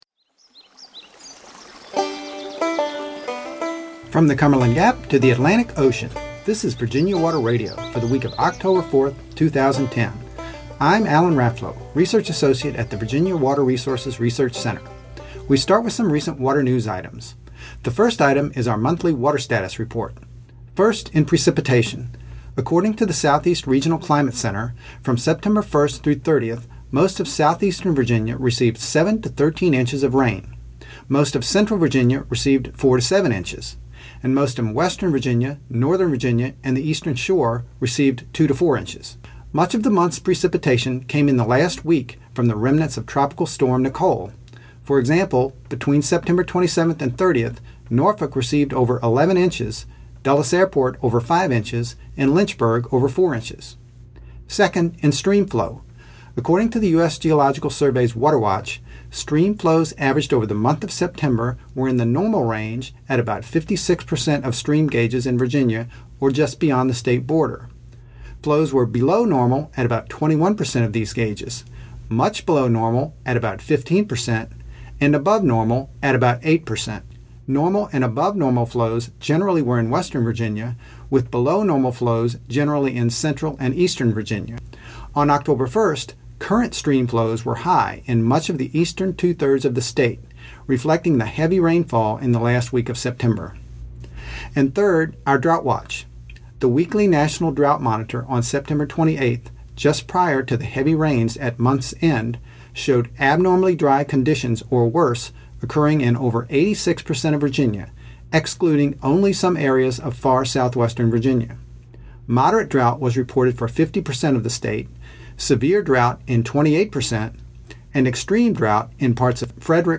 WATER SOUNDS AND MUSIC
This week we feature another mystery sound: The Canada Goose.